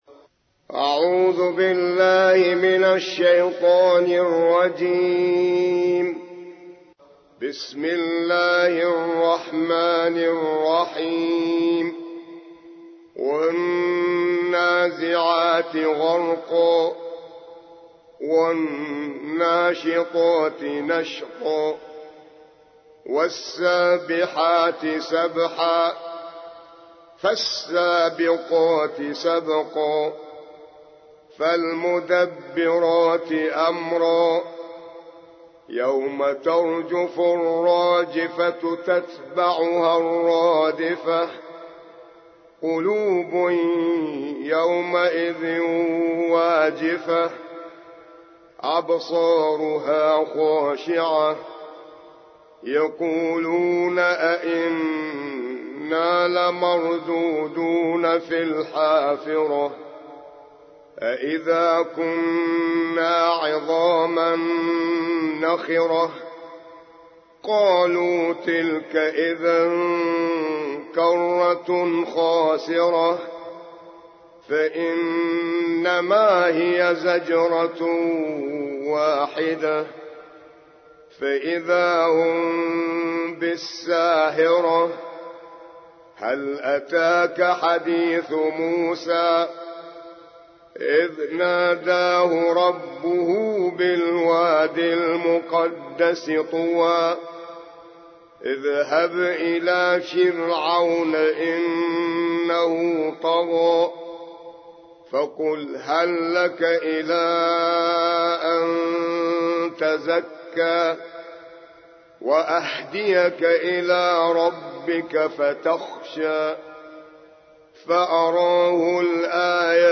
79. سورة النازعات / القارئ